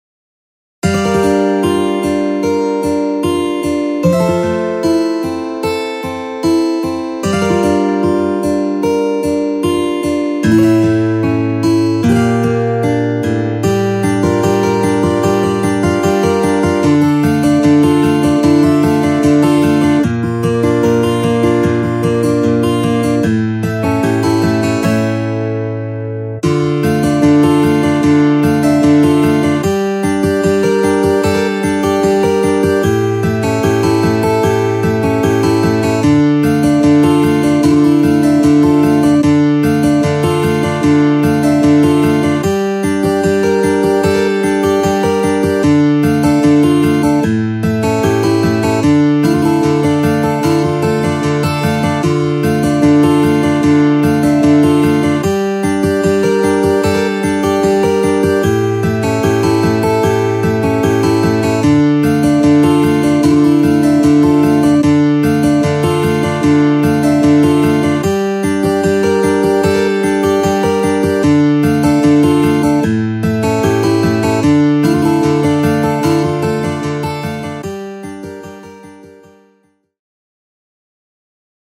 HALion6 : A.Guitar
Bright Acoustic Steel